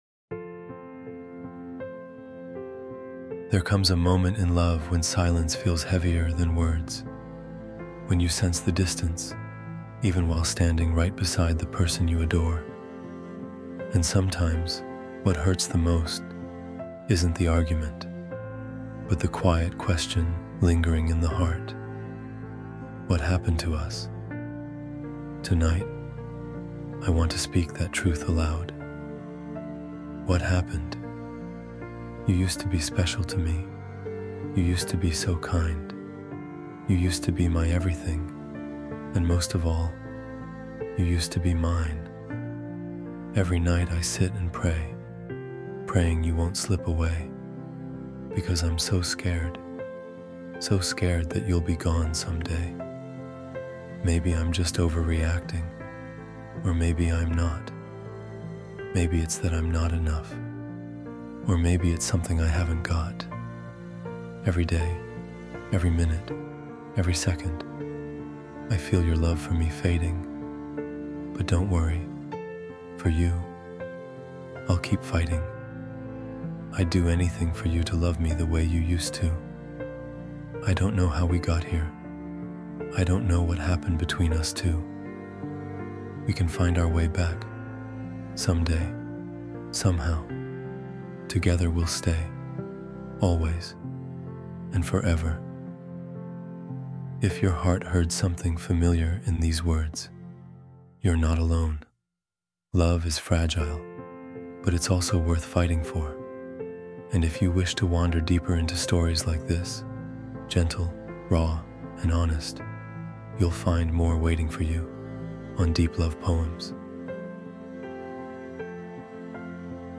What Happened? – Emotional Love Poem for Her (Male Spoken Word) What Happened?
what-happened-love-poem-for-her.mp3